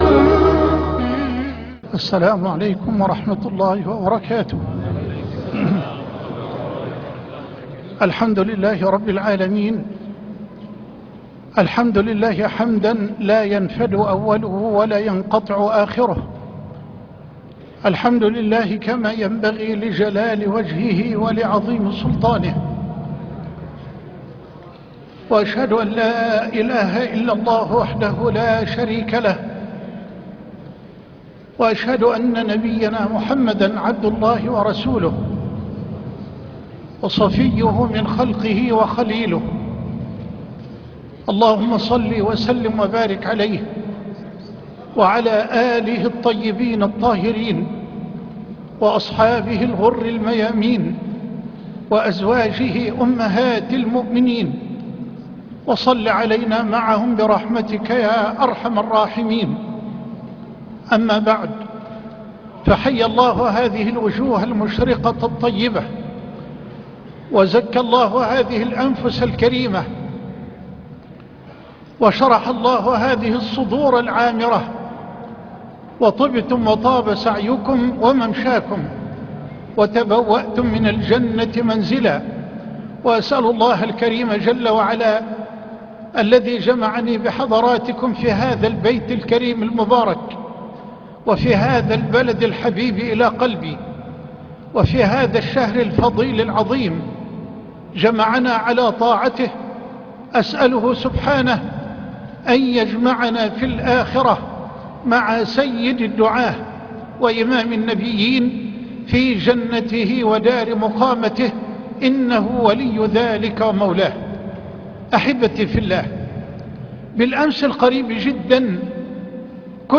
صفات المسارعين الى الخيرات - درس للشيخ محد حسان بدولة الكويت - فضيلة الشيخ محمد حسان